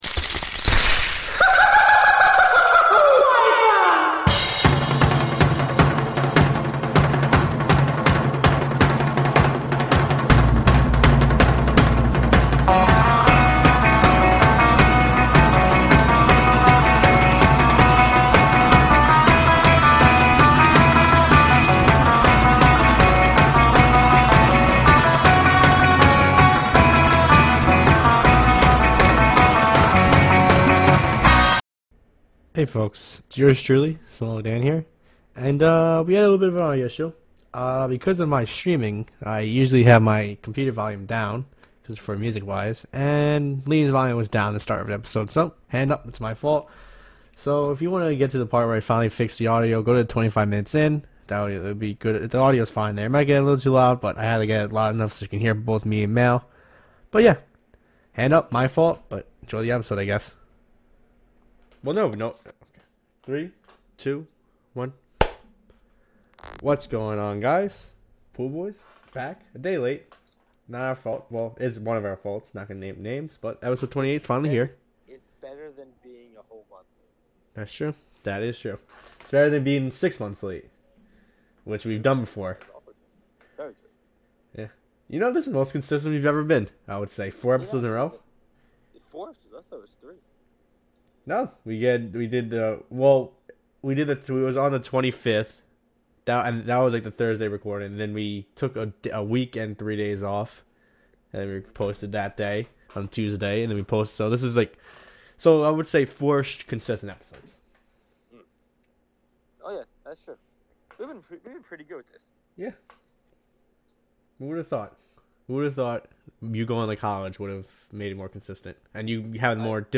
Episode 28 of Pool Boys is now live and we had audio problems. To get past the audio problem go to the 25th minute mark in the podcast.